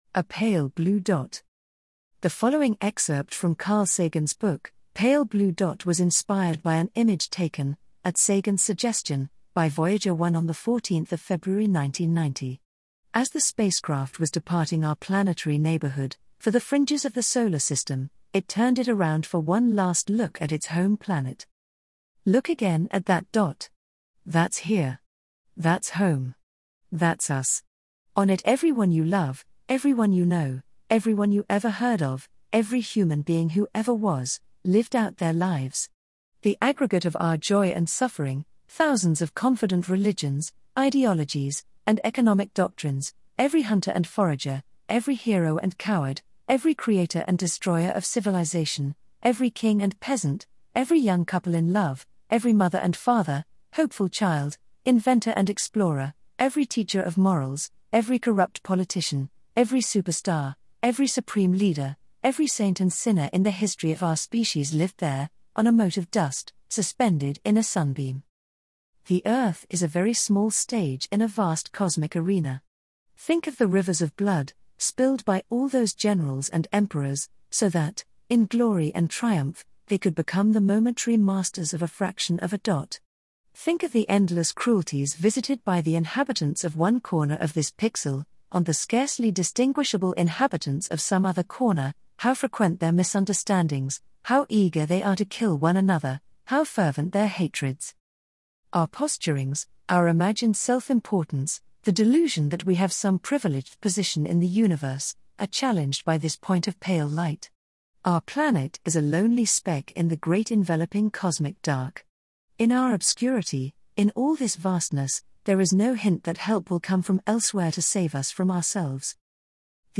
AI powered text-to-speech for converting your articles, blogs and newsletters to audio.
Listen to audio created in prosodie.